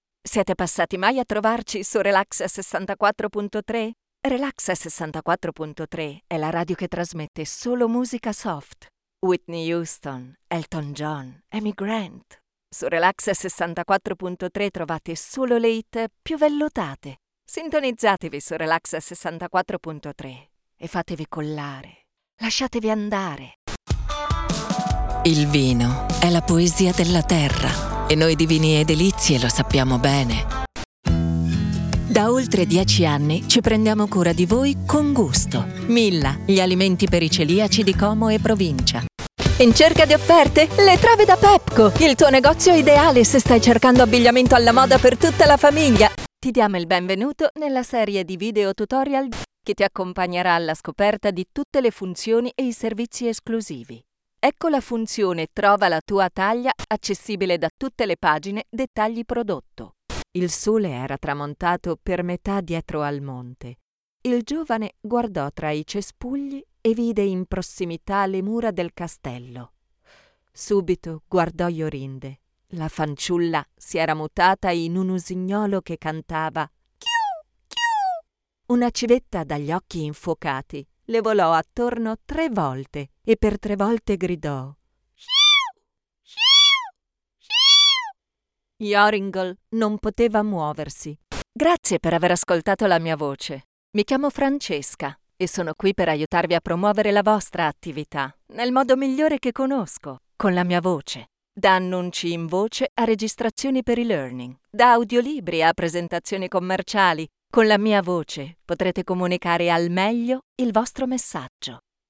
articulate, authentic, Deep, elegant, empathic, Formal, friendly
I record every day in my Home Studio: commercials, e-learning courses, tutorials, documentaries, audio guides, messages for telephone exchanges, audio for promo videos, narrations for radio broadcasts, audiobooks, audio for videogames, and much more.